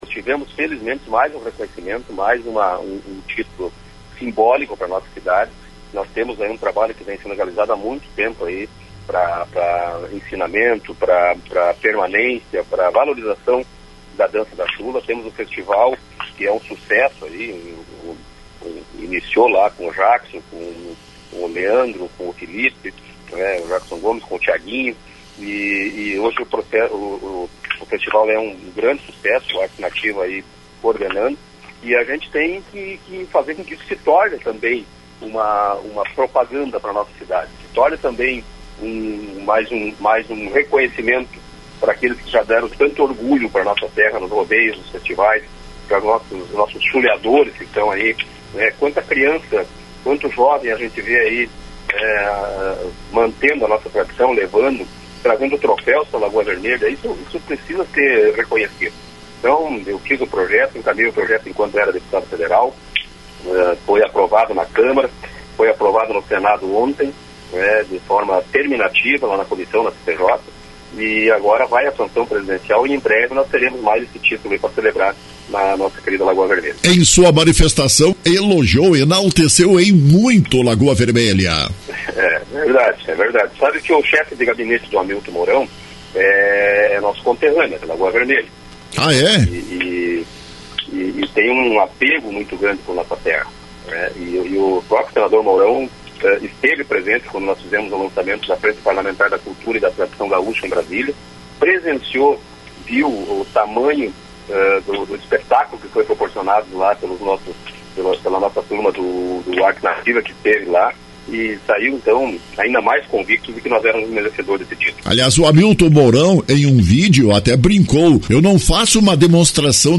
Projeto agora vai à sanção presidencial. Santini foi ouvido pela Rádio Lagoa FM.